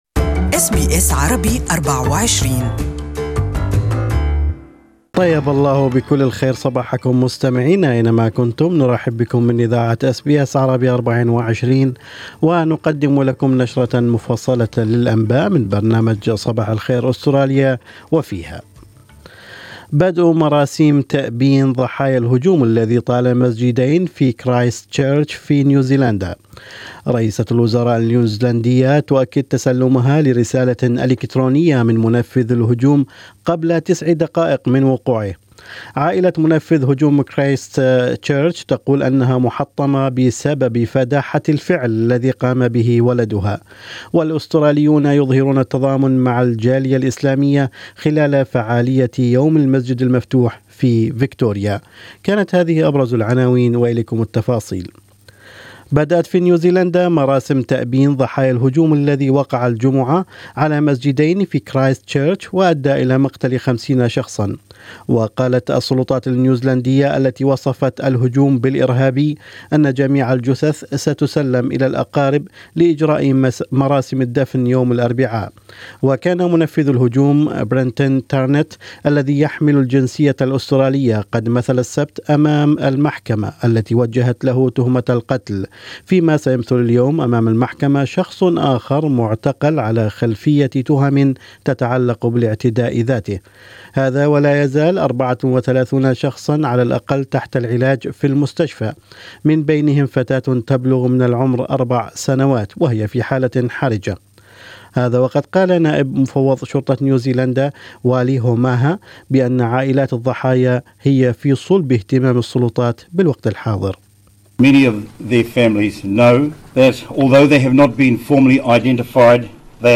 نشرة الاخبار: أرديرن تلقت بياناً من المهاجم قبل 9 دقائق من تنفيذه الاعتداء